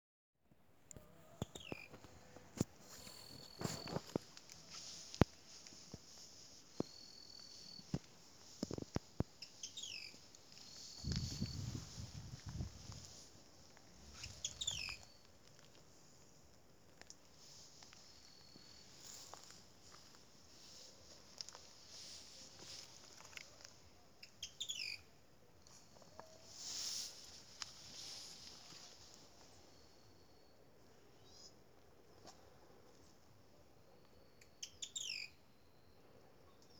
Mosqueta Ojo Dorado (Hemitriccus margaritaceiventer)
Nombre en inglés: Pearly-vented Tody-Tyrant
Localidad o área protegida: Dique El Cadillal
Condición: Silvestre
Certeza: Vocalización Grabada
mosqueta-ojo-dorado-mp3.mp3